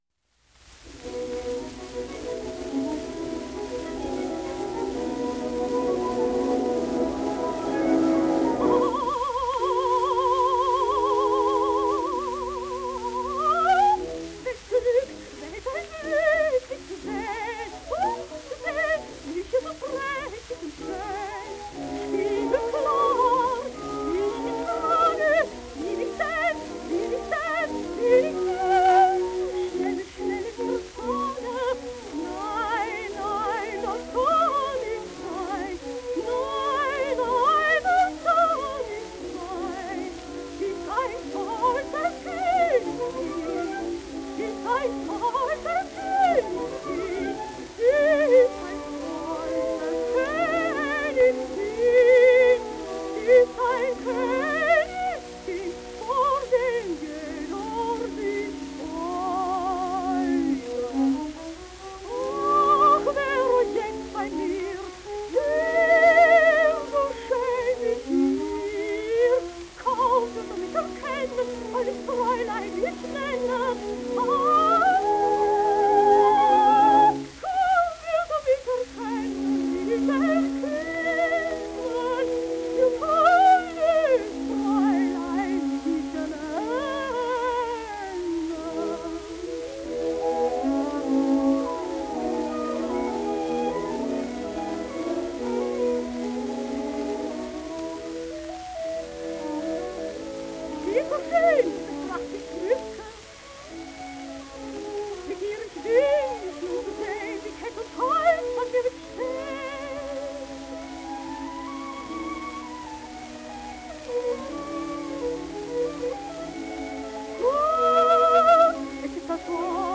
09 - Elisabeth Schumann - Gounod. Faust - Ah! je ris de me voir si belle (Sung in German) (1920)